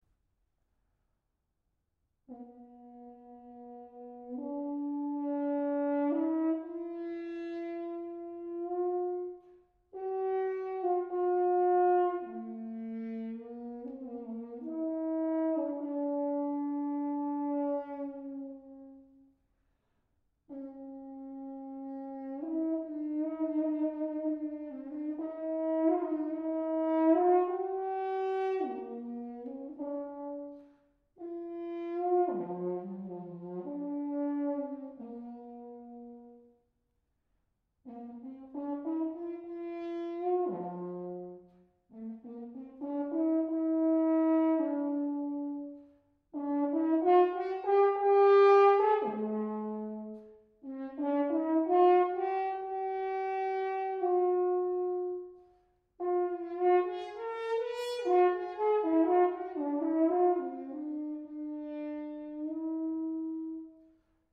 solo natural horn
Gallay explores a remarkable range of textures and flights of melodic and thematic invention in these works, from long lyrical lines to fleet passagework to heroic fanfares. The timbral modulation is particularly notable, showcasing the instrument’s capacity to move from brassy to mellow and everything in between with facility.